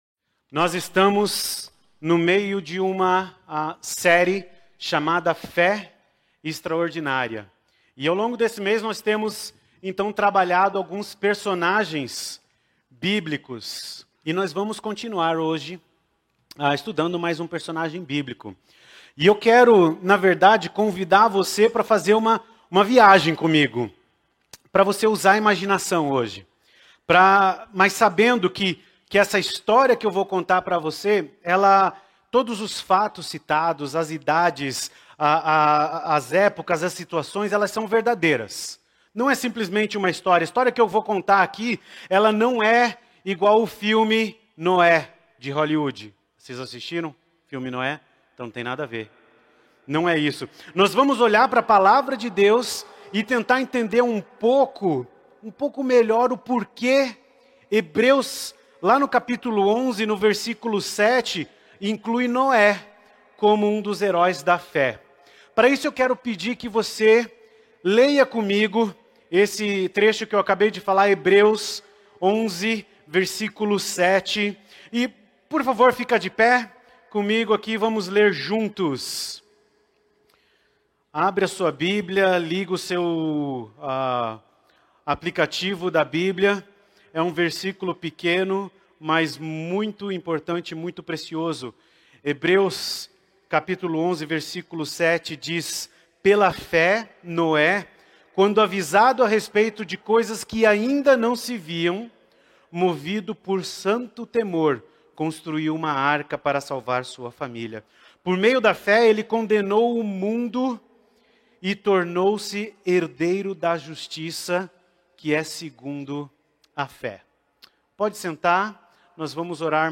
na Igreja Batista do Bacacheri